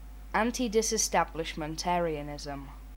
Antidisestablishmentarianism (/ˌæntidɪsɪˌstæblɪʃmənˈtɛəriənɪzəm/
, US also /ˌænt-/ ) is a position that advocates that a state church (the "established church") should continue to receive government patronage, rather than be disestablished (i.e., be separated from the state).[1][2]